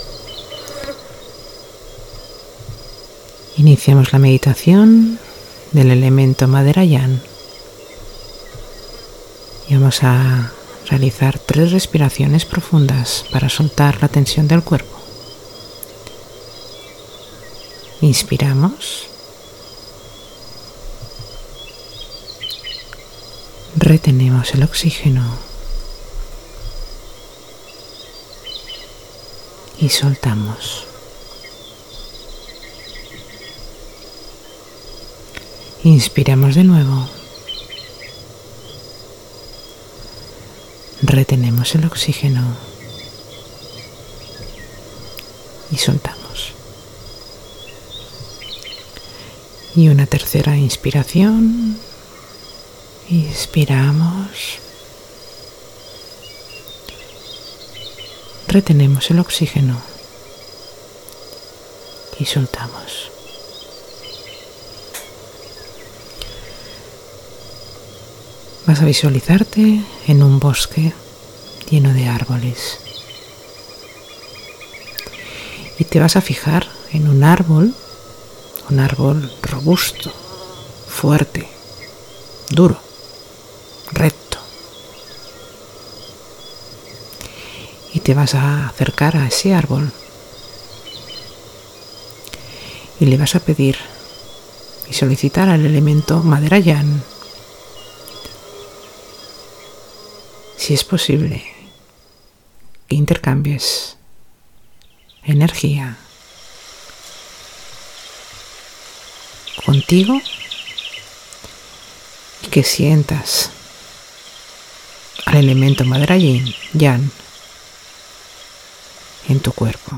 Meditación – Madera Yang